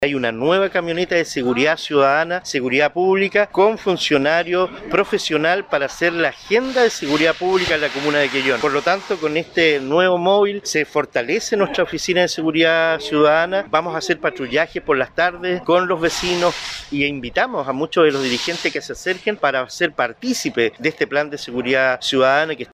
En tanto, el Alcalde Cristian Ojeda dijo que la agenda de Seguridad Pública es un instrumento que permitirá focalizar las zonas de mayor acción delictual.